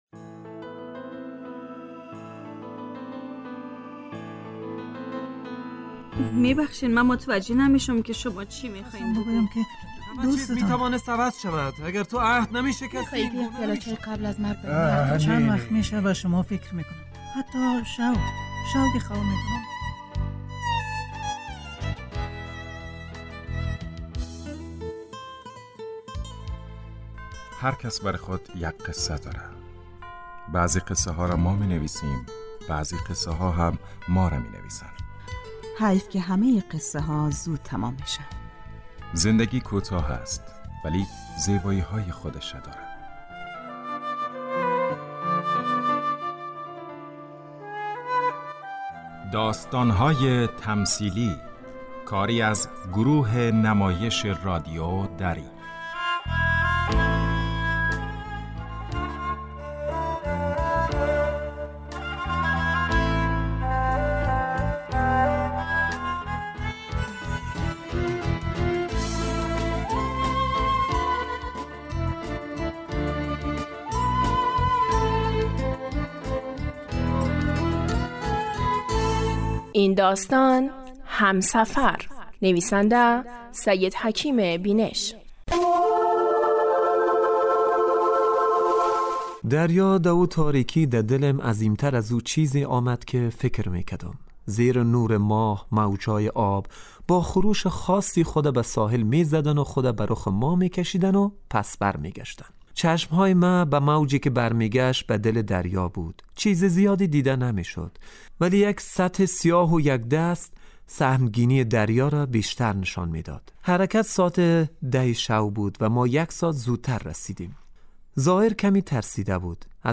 داستان تمثیلی / همسفر